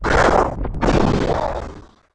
Index of /App/sound/monster/chaos_ghost